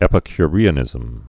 (ĕpĭ-ky-rēə-nĭzəm, -kyrē-)